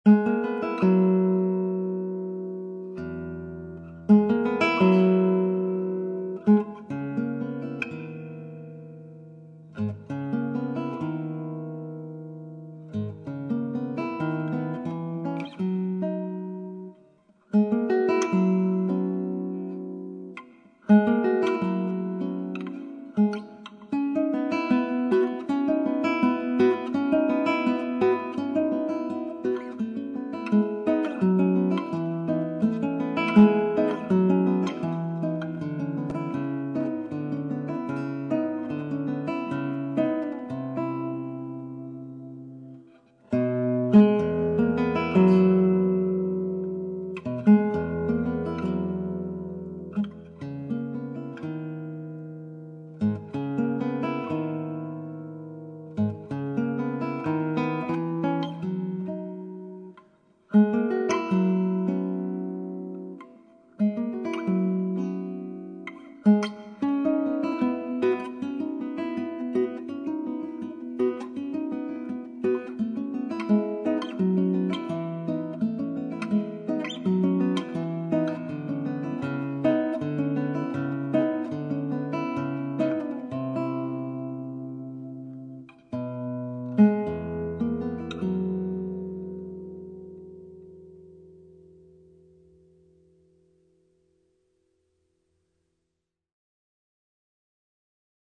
6 cordes
Enregistré à la Salle "Le Royal" de Pessac (Gironde)